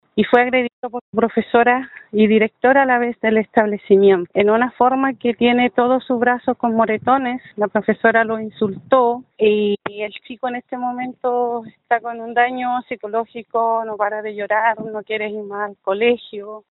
Según explicó la tía del niño, de manera anónima, esto generó no solo un daño físico en él, sino que también psicológico.